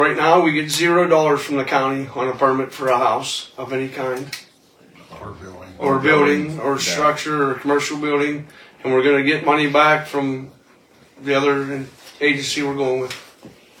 Supervisors Chairman Matt Housholder said that they want to change providers as they wish to get some money for inspections.